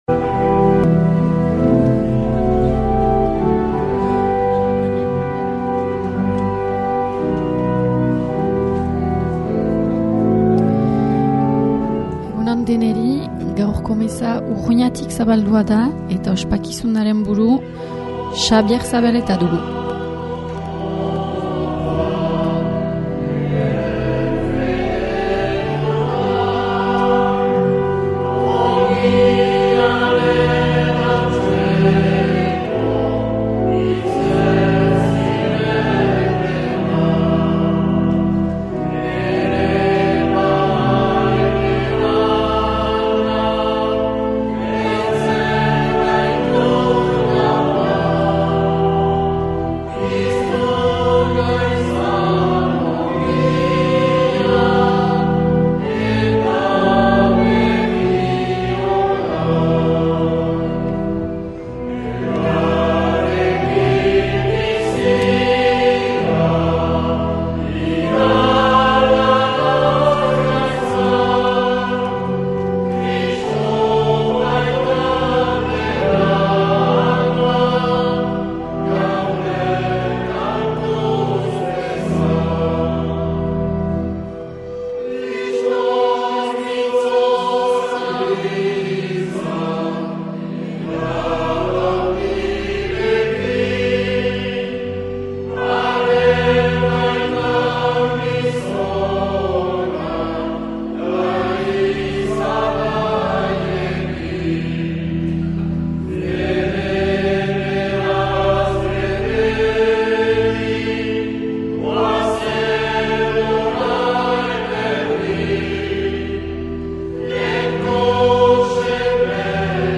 2025-02-23 Urteko 7. Igandea C - Urruña